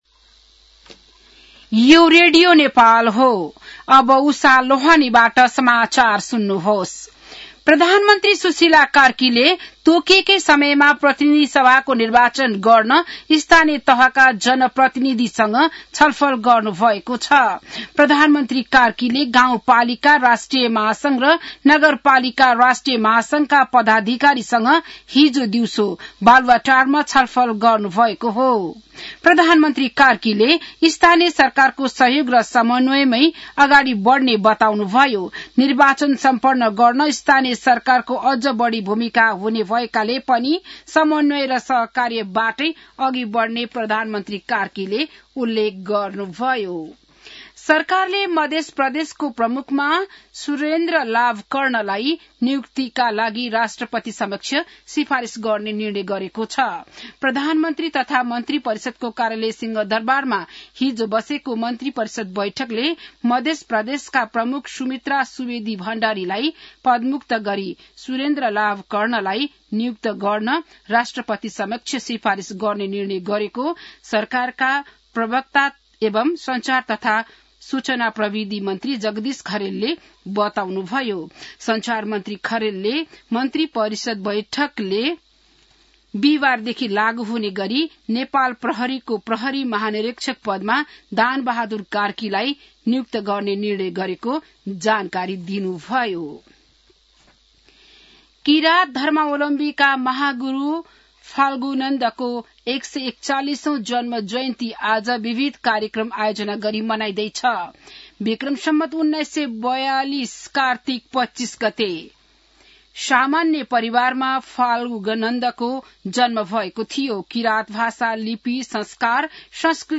बिहान १० बजेको नेपाली समाचार : २४ कार्तिक , २०८२